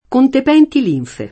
kon tep$nti l&nfe] (Monti)